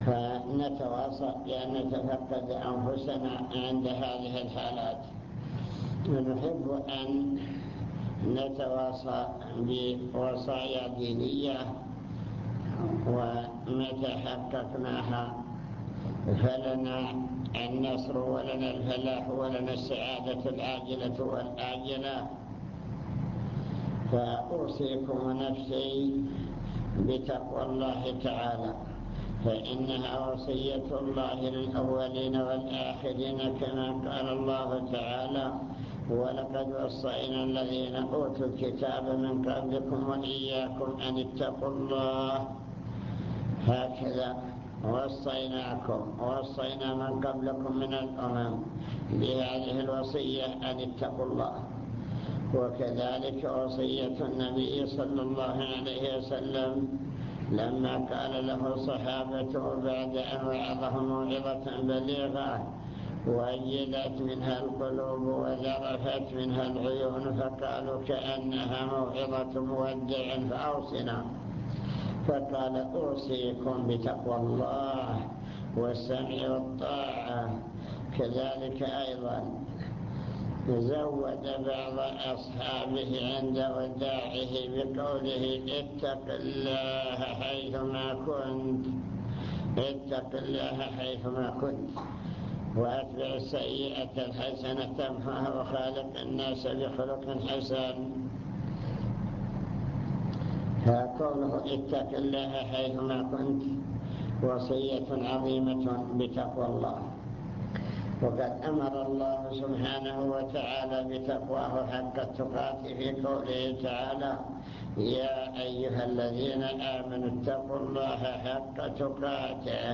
المكتبة الصوتية  تسجيلات - محاضرات ودروس  محاضرة القاعة